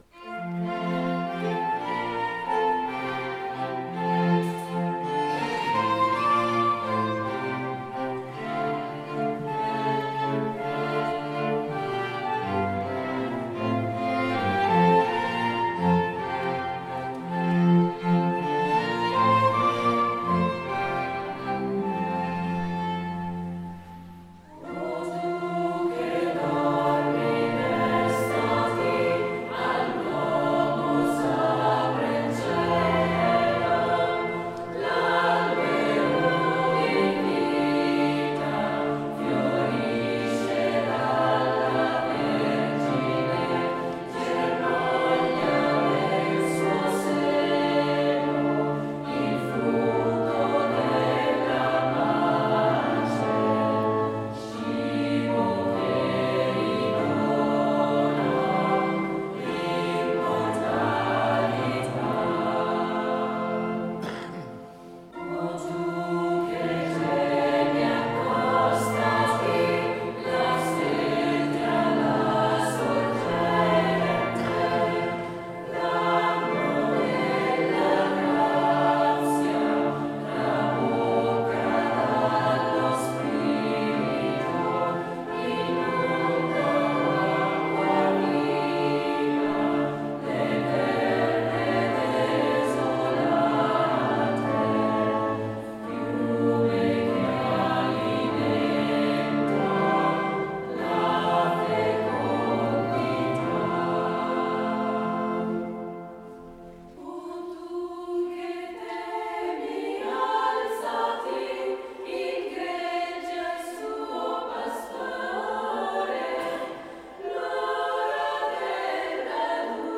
Questo brano tradizionale – tratto dal celebre “In dulci jubilo” – ha la forma di un corale strofico, scelta che può favorire una partecipazione corale dell’assemblea, da sola o in alternanza col coro.
O-tu-che-dormi-destati-coro.mp3